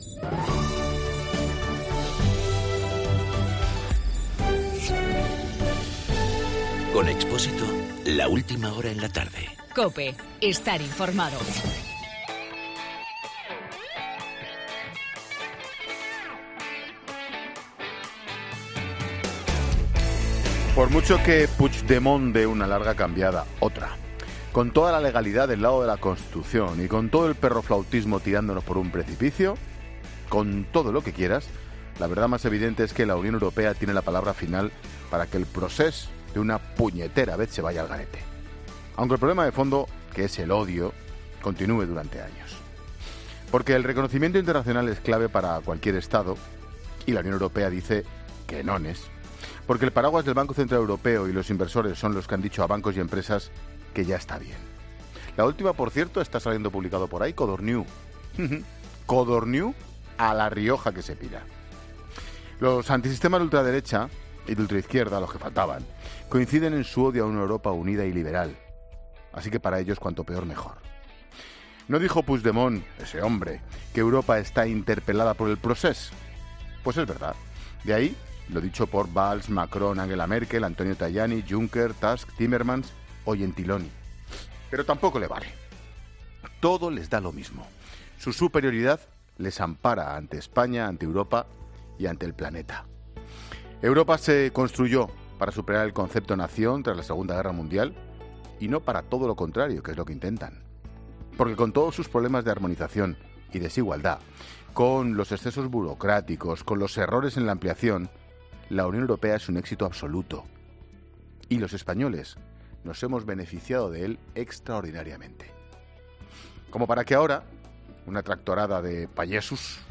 AUDIO: El comentario de Ángel Expósito.